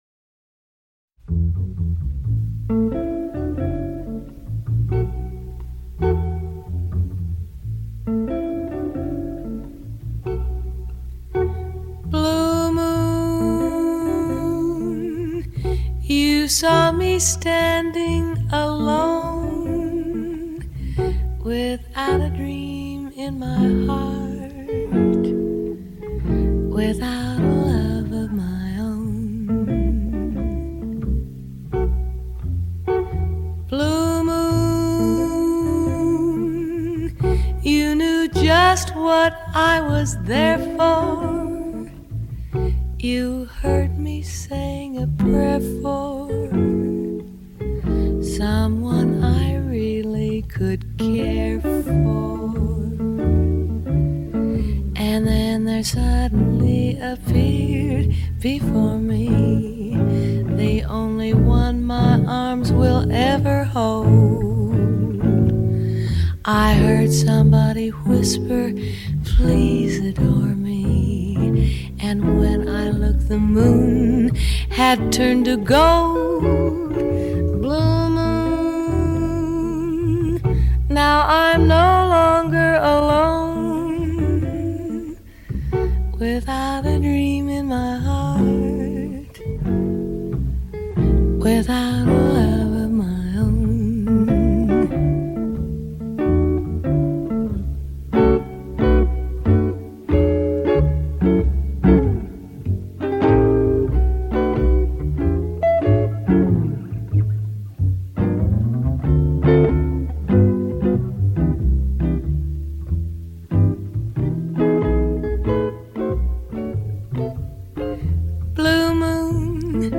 Cool Jazz, Vocal Jazz